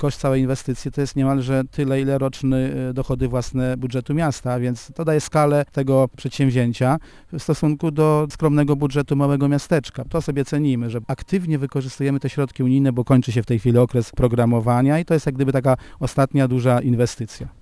- Bez tego wsparcia powstanie takiego obiektu nie byłoby możliwe - podkreśla burmistrz Bodys: